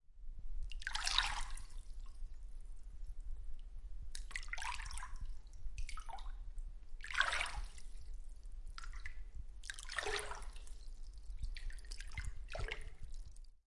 Foley Ambient " 鸟类森林 阿蒂特兰湖 危地马拉 Arka
描述：在arka冥想中心附近录制危地马拉
标签： 环境 福利
声道立体声